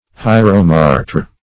Search Result for " hieromartyr" : The Collaborative International Dictionary of English v.0.48: Hieromartyr \Hi"er*o*mar`tyr\, n. [Gr.